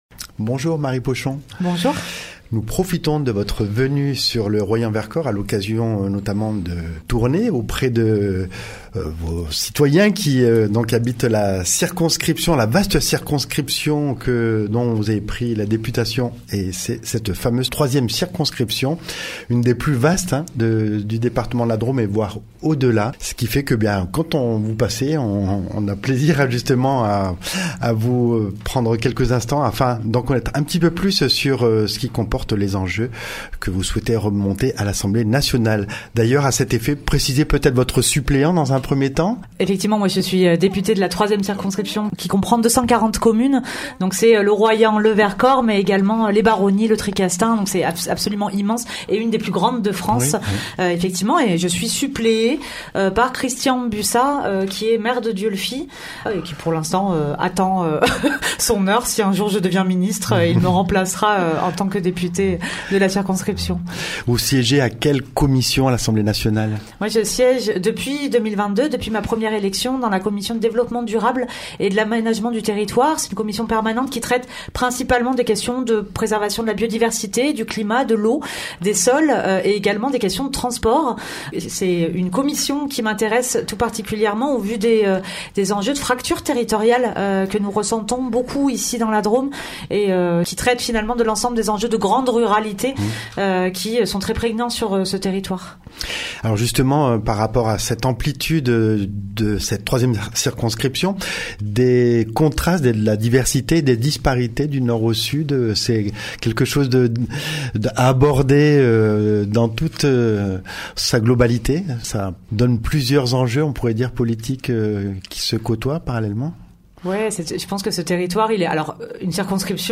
Rencontre avec Marie Pochon au studio de Radio Royans Vercors
L’occasion d’évoquer avec elle le Fond de soutien à l’expression radiophonique, la particularité de notre territoire, les enjeux politiques et économiques actuels. Elle s’est aussi prêtée au jeu de l’interview à notre micro.